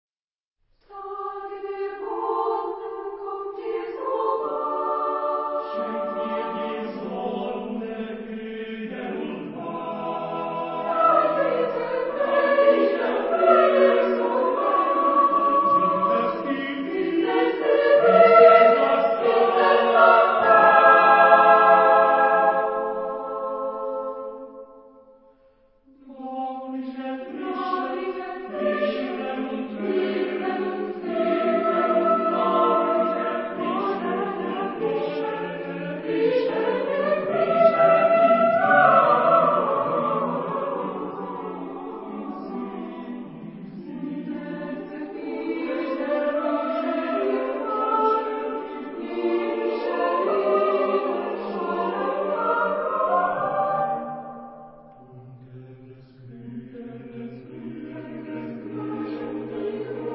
Género/Estilo/Forma: Profano ; Lírica ; Coro
Tipo de formación coral: SATB  (4 voces Coro mixto )
Tonalidad : tonal